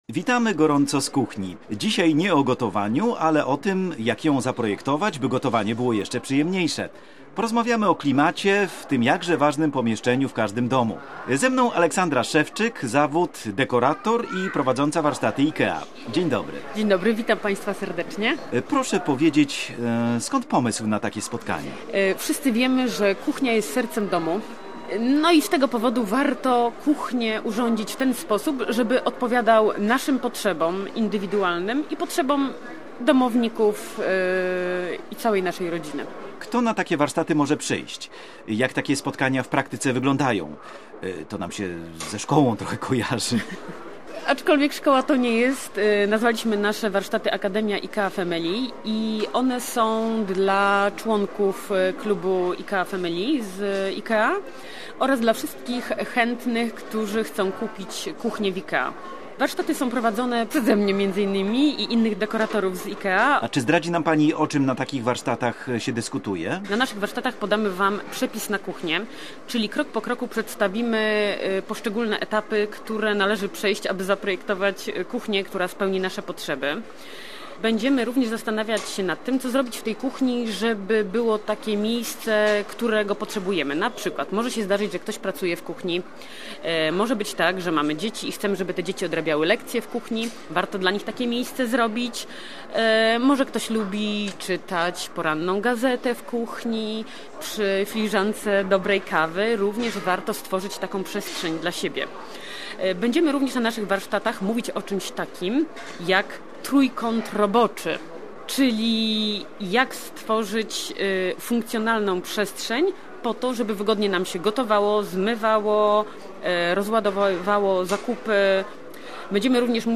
Audycja dotycząca projektowania kuchni i o tym, co zrobić, aby gotowanie było jeszcze przyjemniejsze.